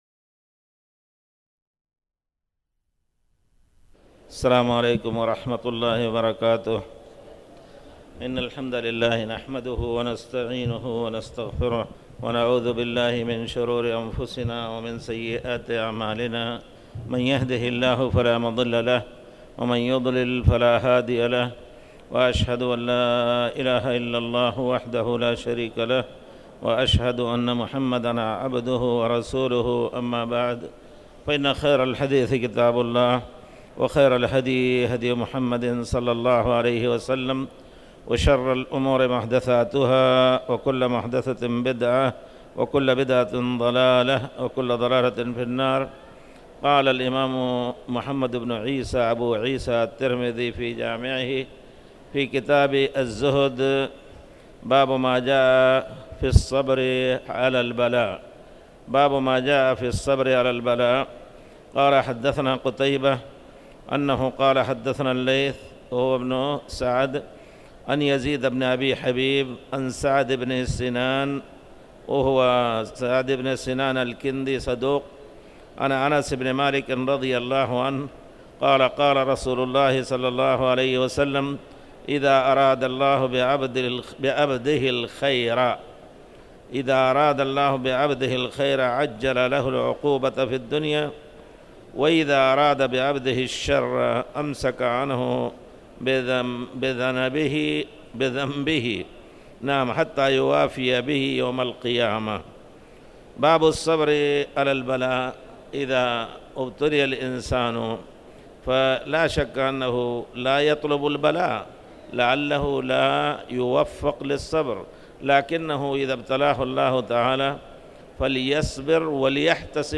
تاريخ النشر ٢٤ جمادى الأولى ١٤٣٩ هـ المكان: المسجد الحرام الشيخ